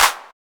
LEX Clap(2).wav